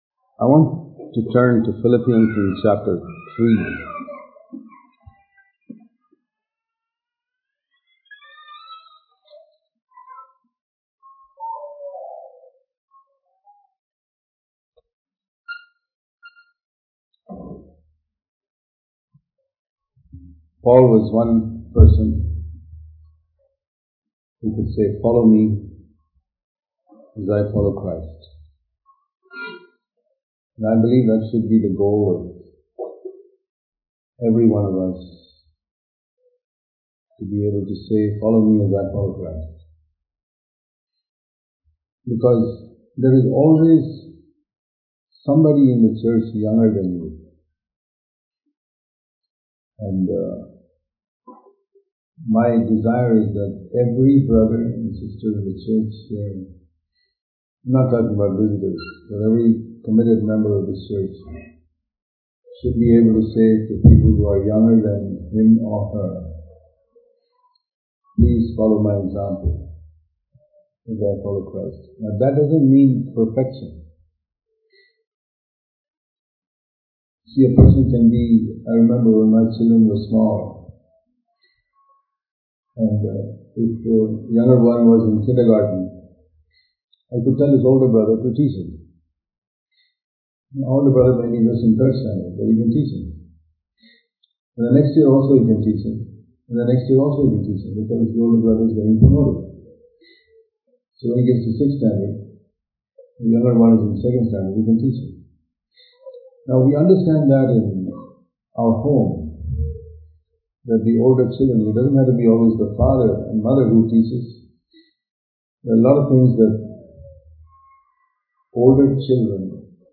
Inviting Others To Follow Us As We Follow Christ Dubai Special Meetings 2018
Sermons